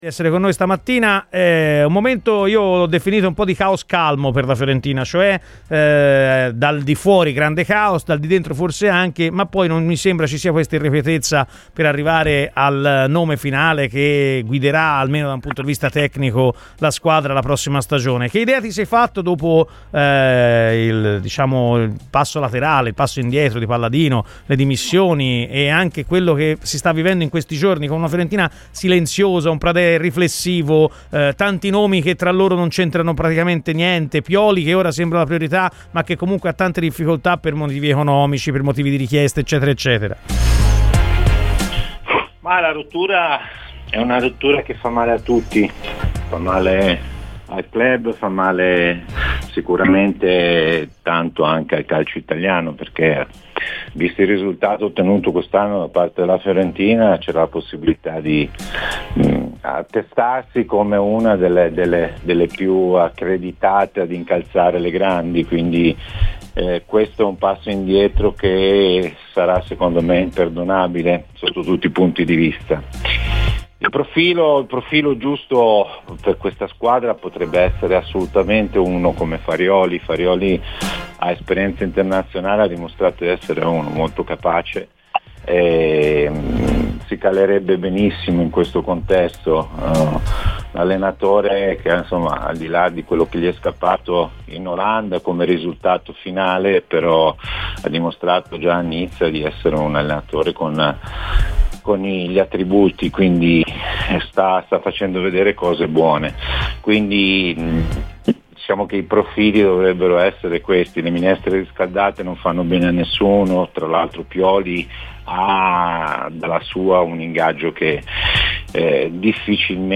è intervenuto a Radio FirenzeViola durante la trasmissione 'Chi Si Compra?', parlando della situazione in casa Fiorentina